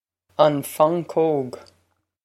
Pronunciation for how to say
on fon-cogue
This is an approximate phonetic pronunciation of the phrase.